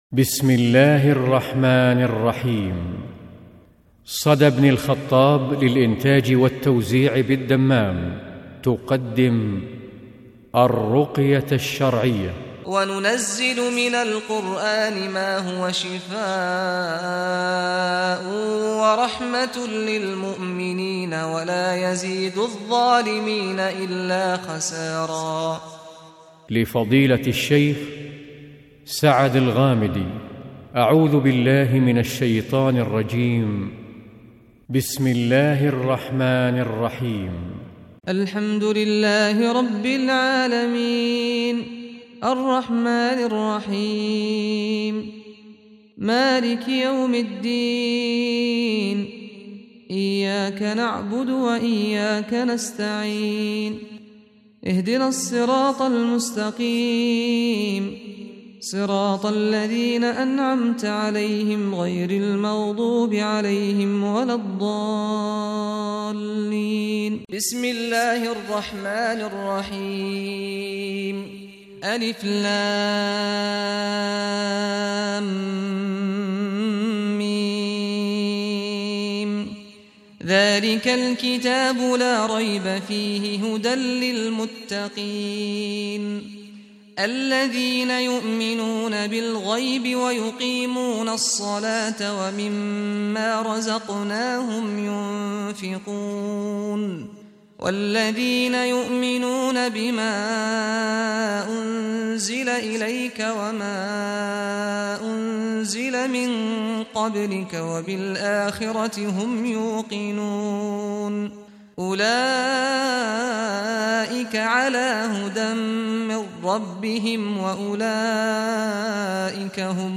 الرقية الشرعية بصوت الشيخ سعد الغامدي - قسم أغســــل قلــــــبك1